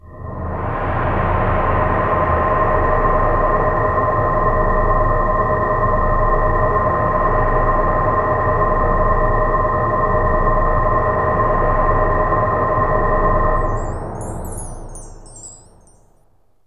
AMBIENT ATMOSPHERES-5 0010.wav